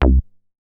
MoogDumb 005.WAV